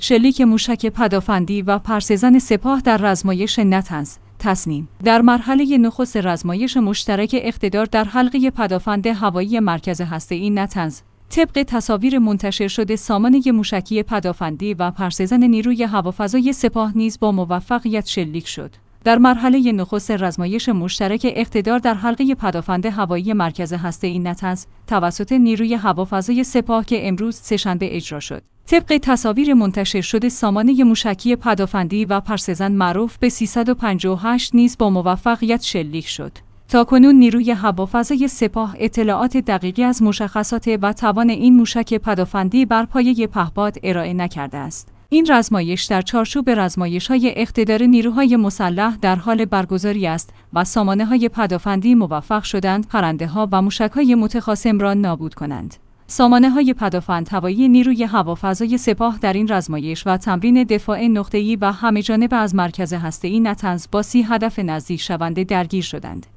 شلیک موشک پدافندی و پرسه‌زن سپاه در رزمایش نطنز